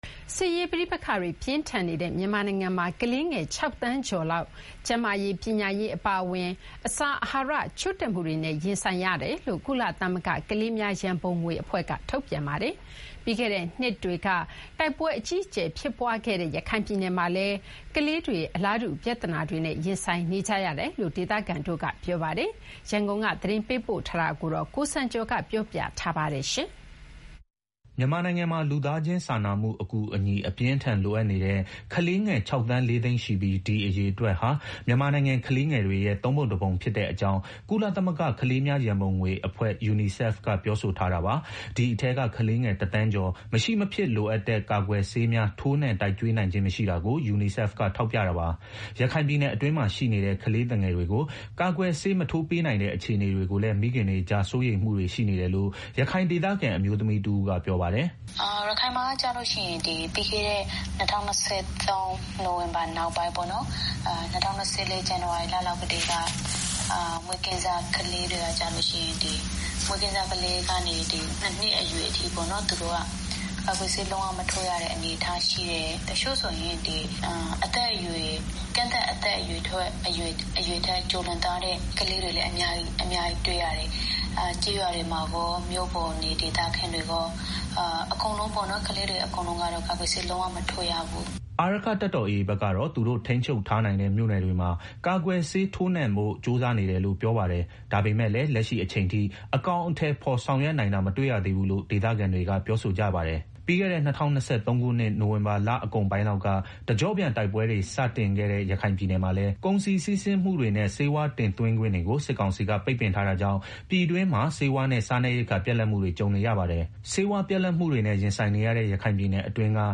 အခုလိုအခြေအနေတွေကြောင့် ရခိုင်ပြည်နယ်အတွင်းမှာရှိနေတဲ့ ကလေးသူငယ်တွေကို ကာကွယ်ဆေး ထိုးမပေးနိုင်တဲ့အတွက် မိခင်တွေကြား စိုးရိမ်မှုတွေရှိနေတယ်လို့ ရခိုင်ဒေသခံအမျိုးသမီးတဦးက ပြောပါတယ်။